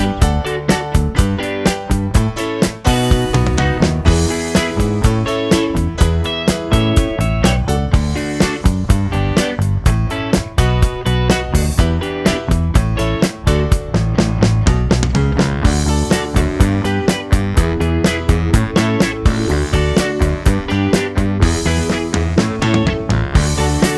No Backing Vocals Soundtracks 3:34 Buy £1.50